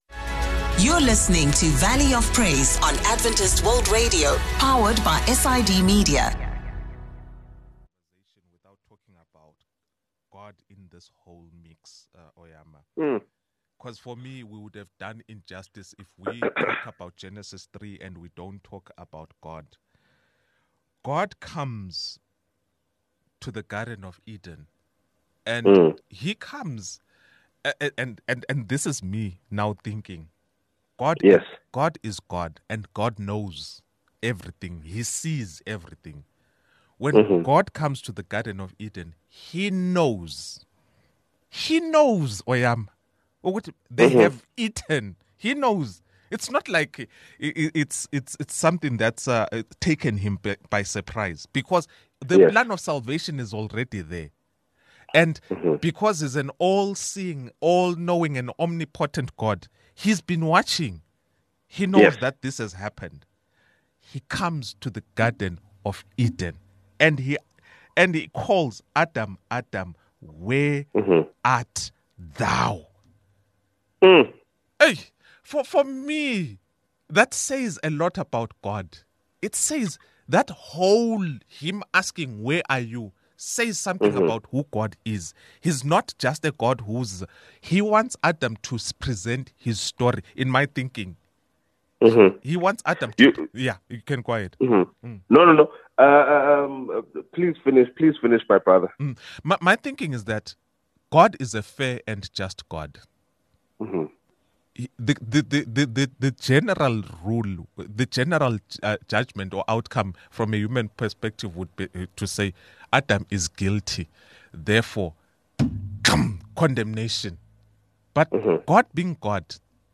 From overcoming obstacles to embracing faith in the workplace, we discuss it all. Get ready for a candid conversation on living out your faith in your profession.